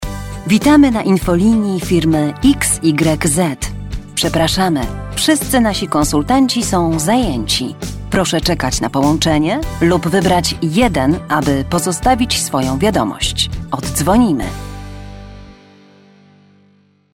Female 30-50 lat
Strong yet clear voice, can sing when needed.